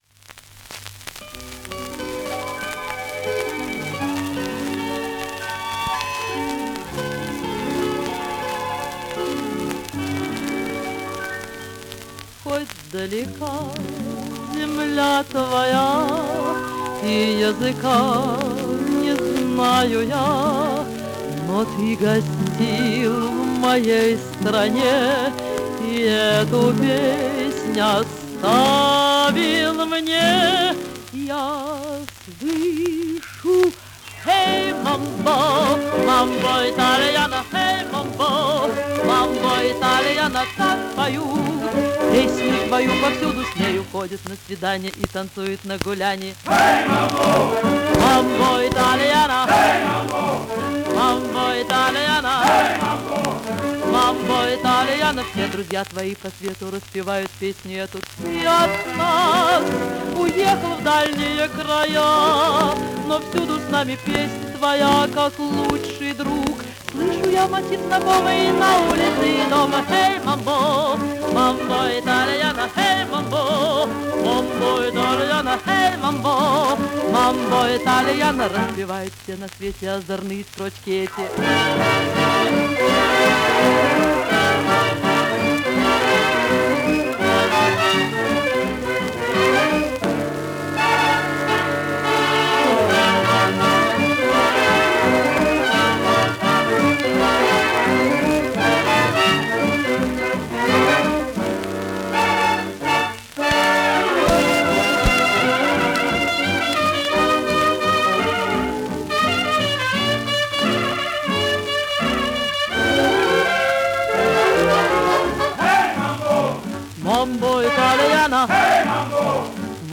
Такое качество - со старой пластинки